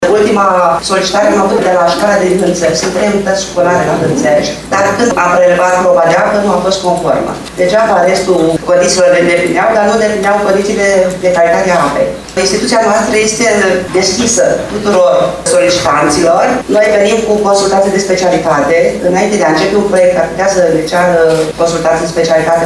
În ședința Colegiului Prefectural de astăzi